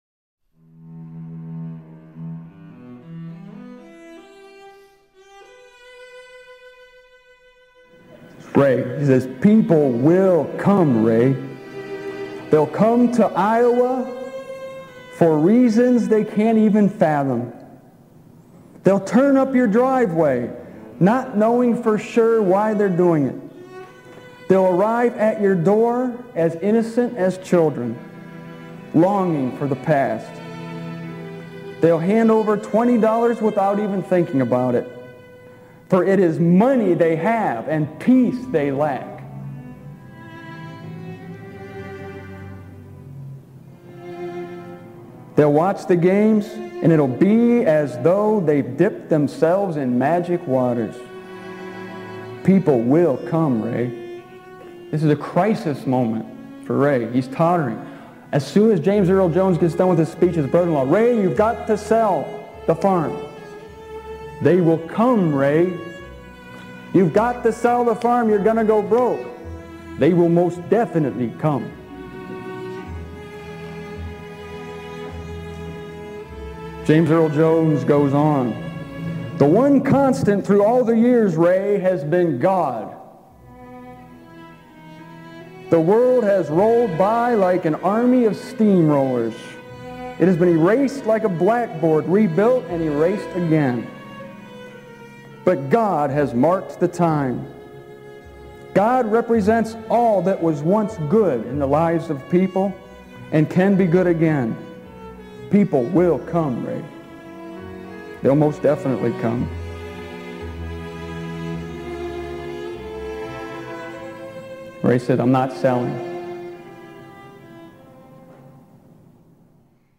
In the summer of 2001, at a conference in Willard, Ohio, I talked about it to the gathered faithful.